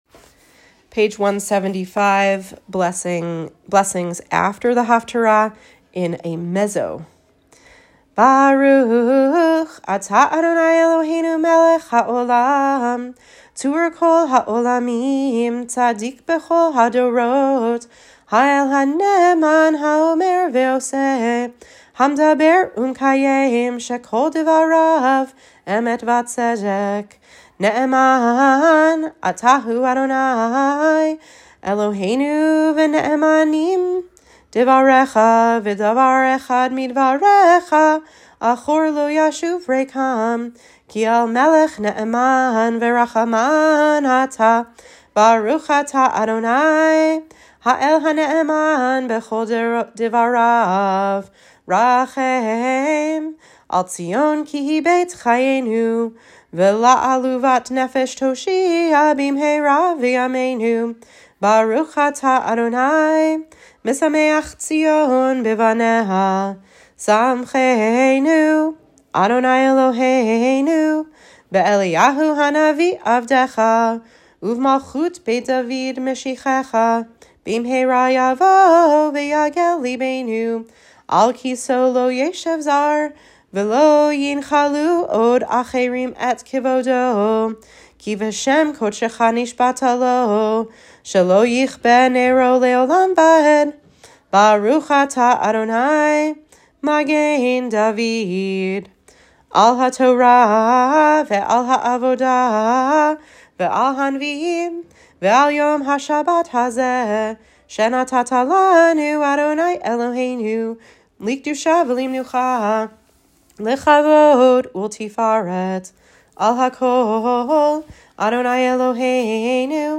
blessing_after_haftarah_mezzo.m4a